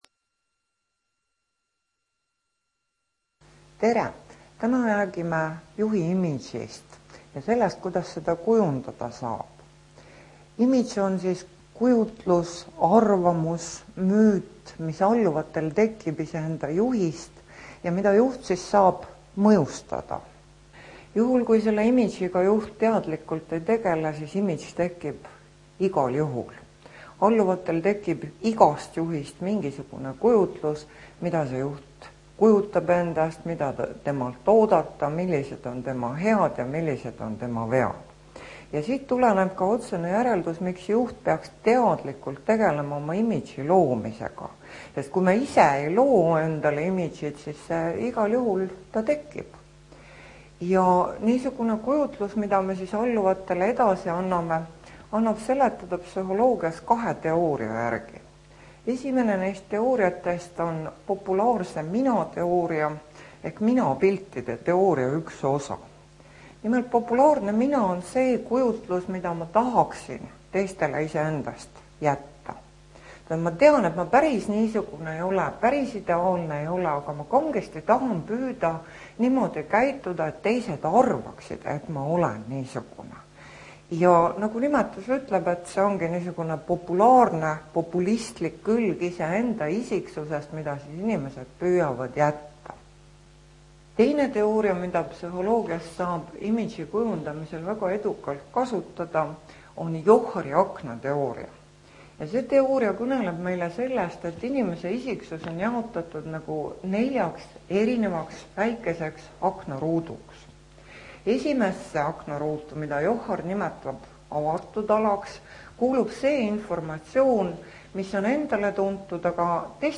Juhi imago loengu MAPP printimiseks Mapi lugemiseks vajate programmi Adobe Acrobat Reader kui teil seda arvutis ei ole siis leiate selle siit Juhi imago loeng MP3 failina (19MB)